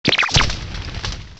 sovereignx/sound/direct_sound_samples/cries/charcadet.aif at master
downsample cries
charcadet.aif